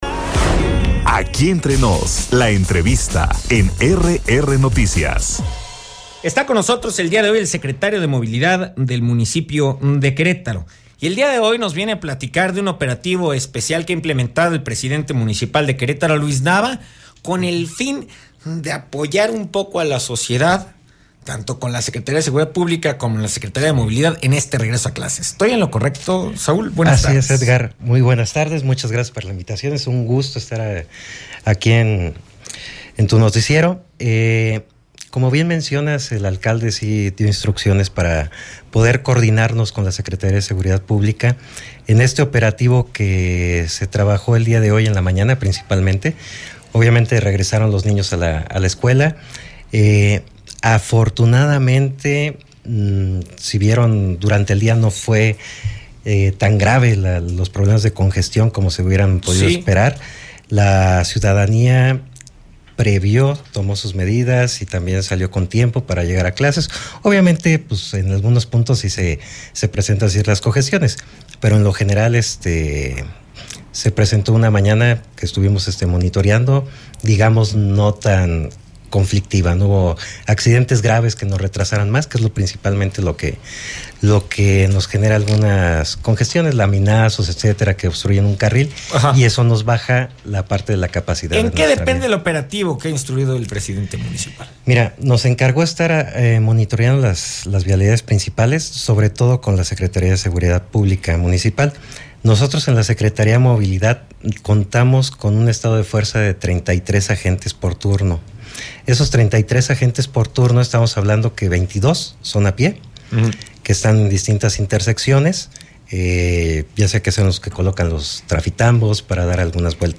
EntrevistasEstadoMultimediaPodcast
ENTREVISTA-SRIO-DE-MOVILIDAD-DE-QRO-SAUL-OBREGON-BIOSCA.mp3